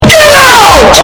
Very Loud Get Out Sound Button - Free Download & Play